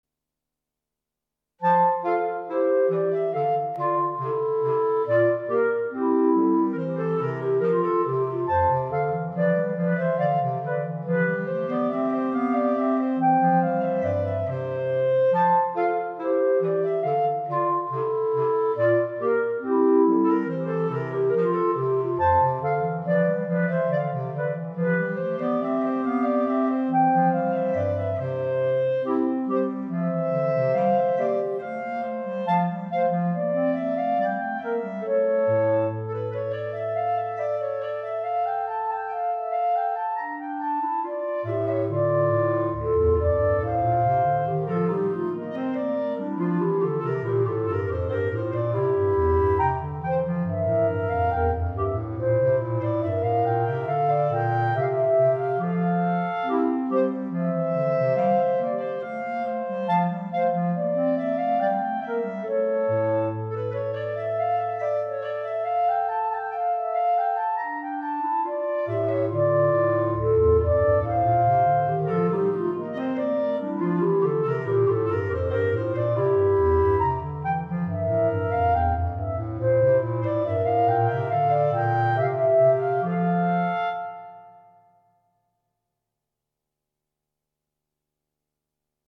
Right click to download Minuet minus Clarinet 3